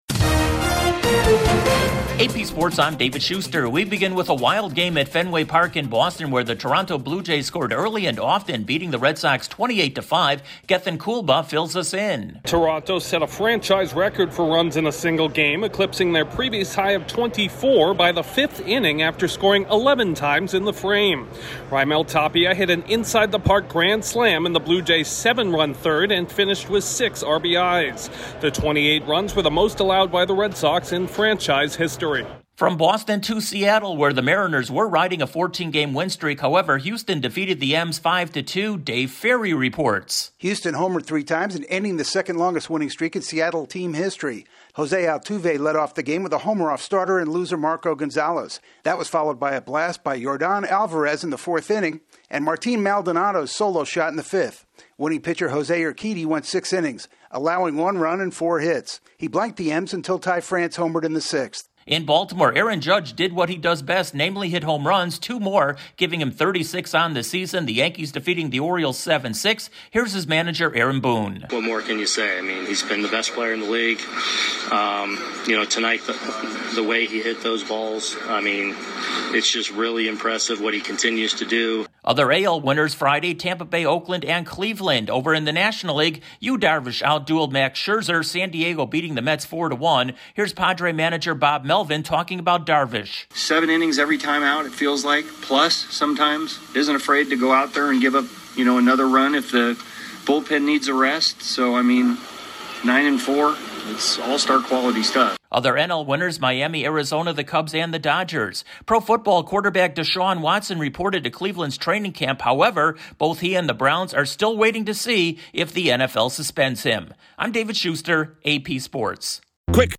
A wild night in Major League Baseball with a record amount of runs scored by Toronto, Aaron Judge continues his torrid home run pace and DeShaun Watson's status remains up in the air despite reporting to the Browns training camp. Correspondent